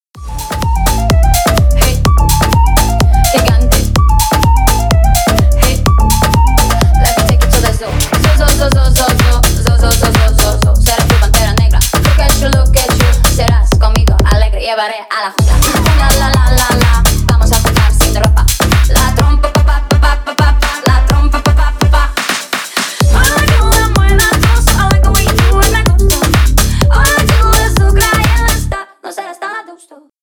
Ремикс
ритмичные # весёлые